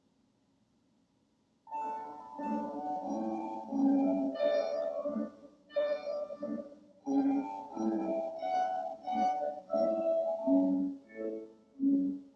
まず、愛らしいガボットの冒頭部、軽やかなスタッカート（赤）に、所々のテヌート（黄）が効いています
gossec-gavotte-begin.m4a